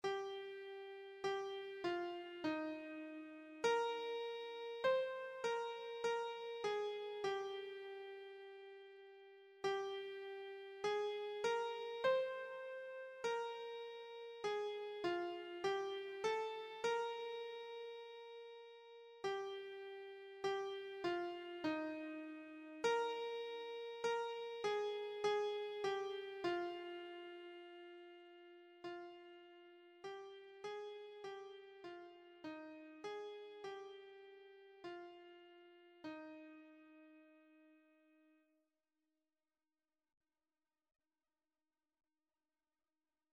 Classical Monk, W.H. Abide With Me (Eventide) Keyboard version
Eb major (Sounding Pitch) (View more Eb major Music for Keyboard )
4/4 (View more 4/4 Music)
Keyboard  (View more Easy Keyboard Music)
Classical (View more Classical Keyboard Music)